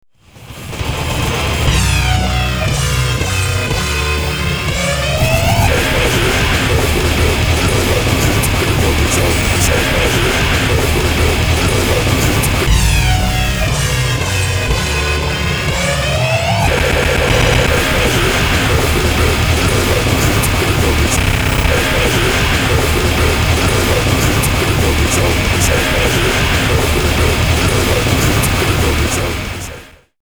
For noise sickos only!
3 song remix CD is pure digital regurgitation